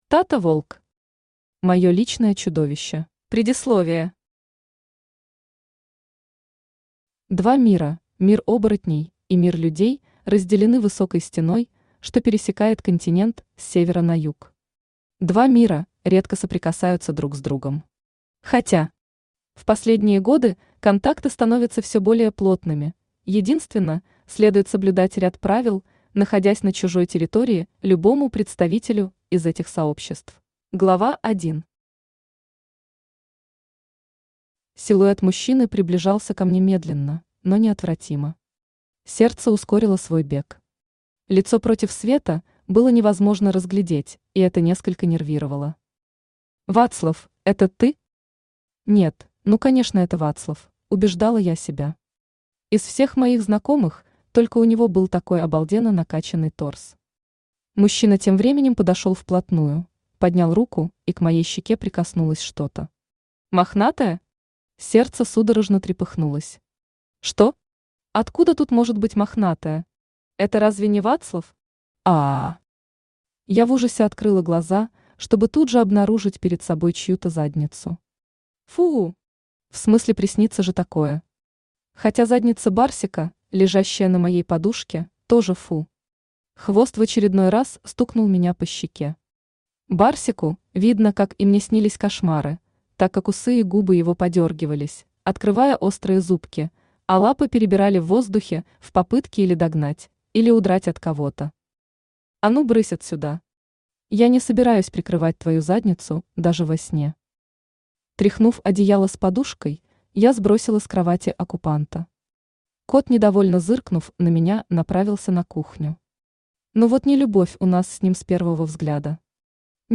Аудиокнига Моё личное чудовище | Библиотека аудиокниг
Aудиокнига Моё личное чудовище Автор Тата Волк Читает аудиокнигу Авточтец ЛитРес.